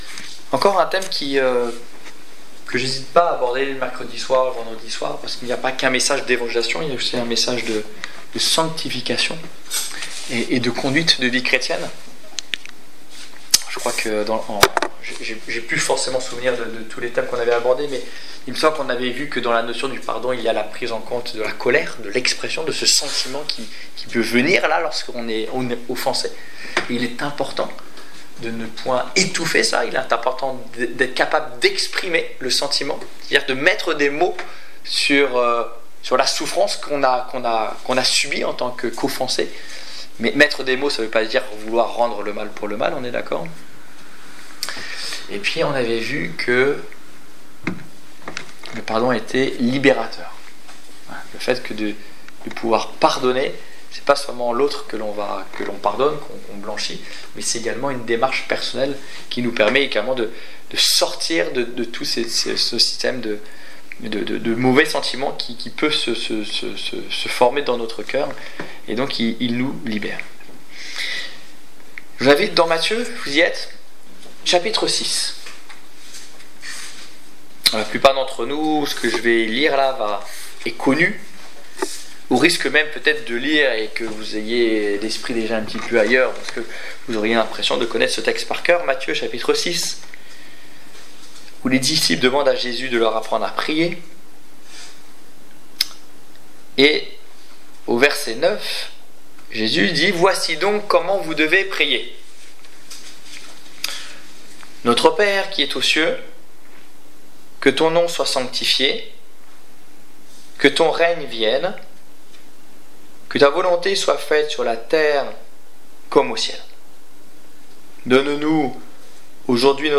Le pardon (4) qui justifie Détails Prédications - liste complète Annonce de l'évangile du 14 décembre 2016 Ecoutez l'enregistrement de ce message à l'aide du lecteur